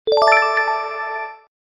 Correct Sound Button - Sound Effect Button